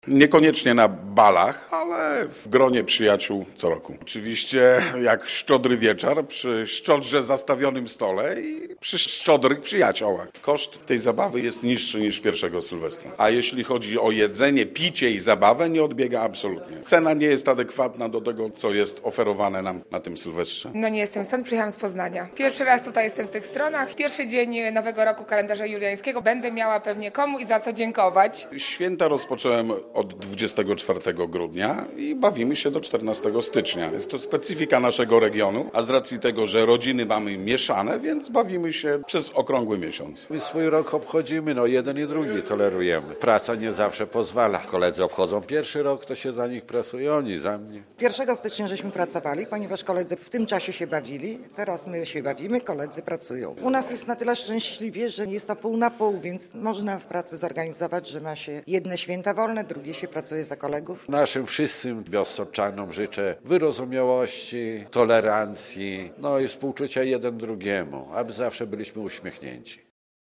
Małanka w regionie - relacja